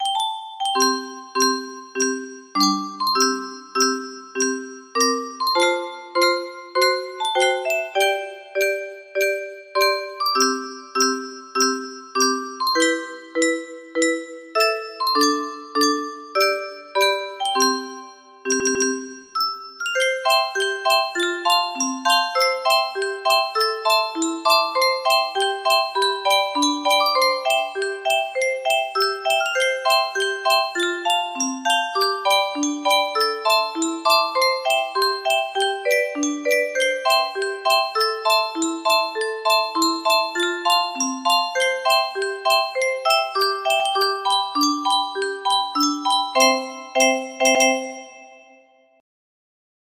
TIẾN QUÂN CA music box melody